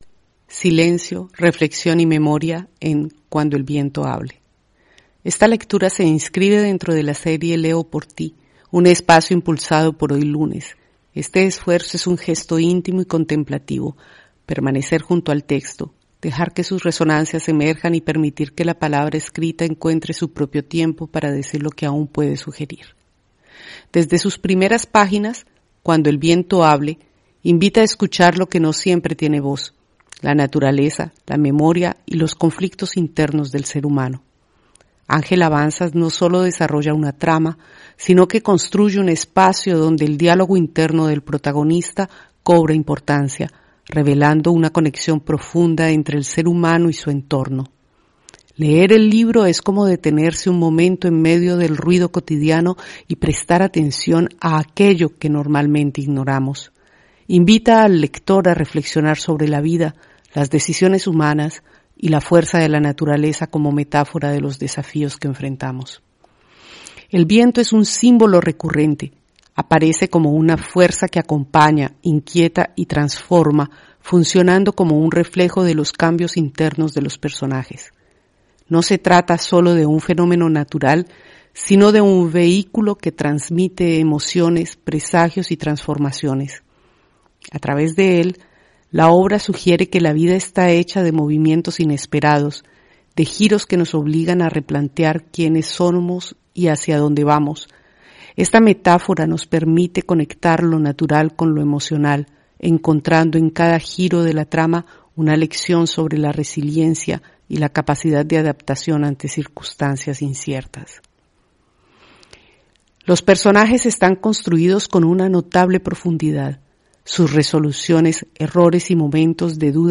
HoyLunes – Esta lectura se inscribe dentro de la serie «Leo por ti», un espacio impulsado por HoyLunes.